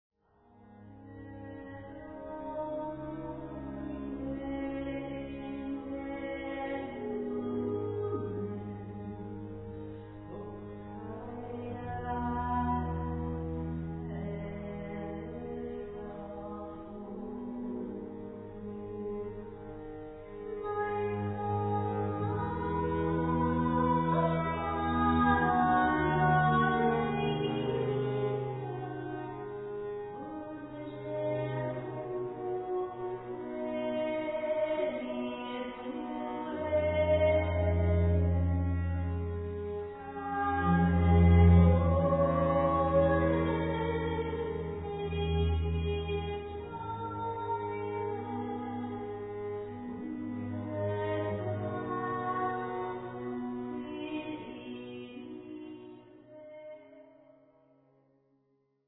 violin, viola